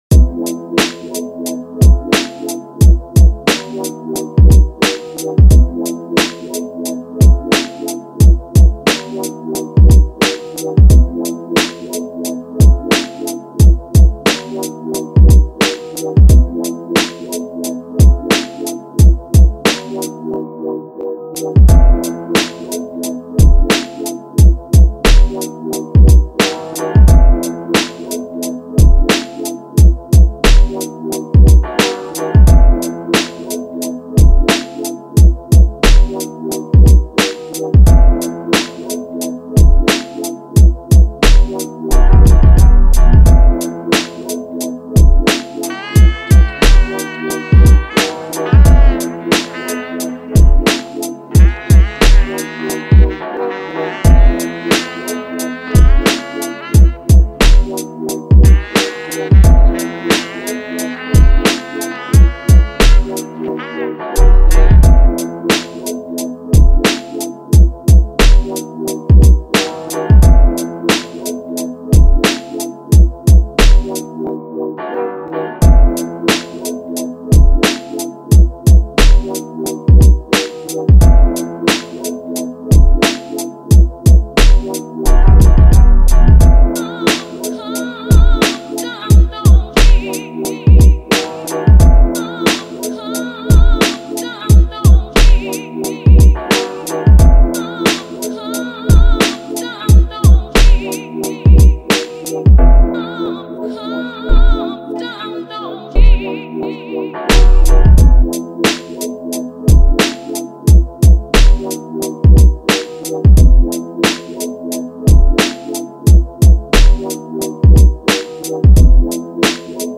so not sure why it sounds a bit sombre